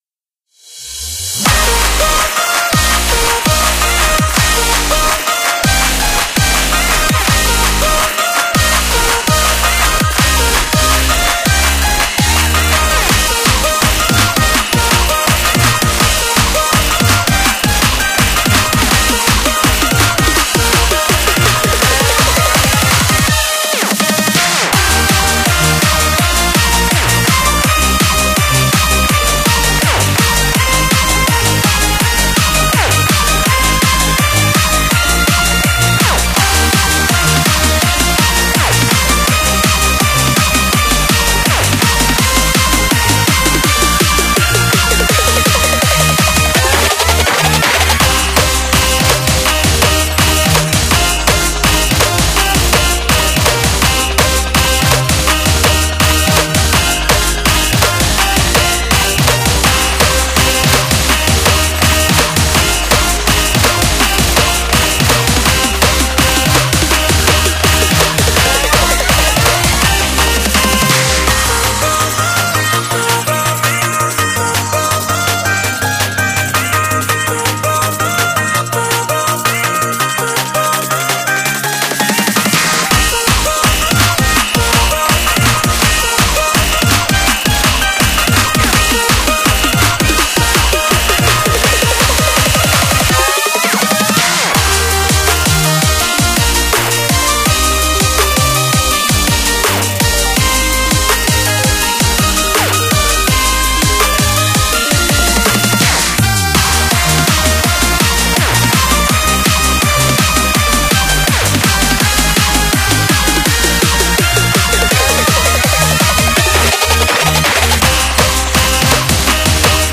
惯例活动BGM：